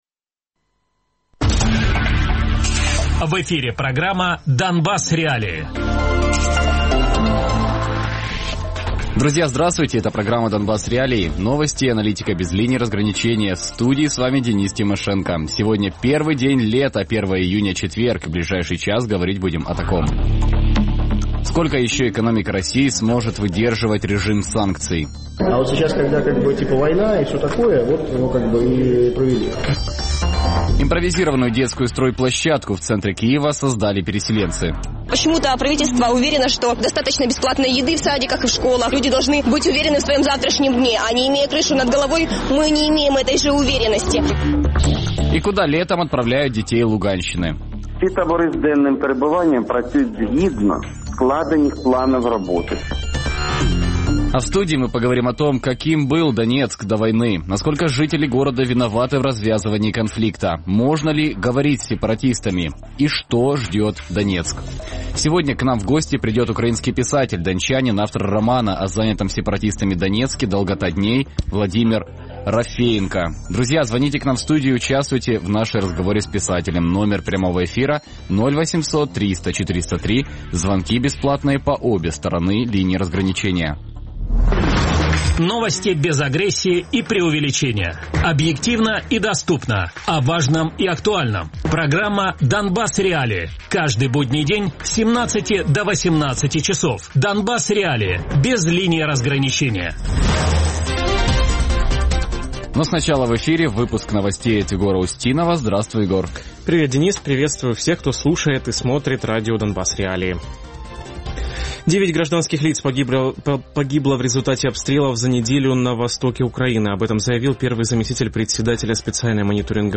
Гость: Владимир Рафеенко, украинский писатель, дончанин, автор романов "Демон Декарта" и "Долгота дней" Радіопрограма «Донбас.Реалії» - у будні з 17:00 до 18:00.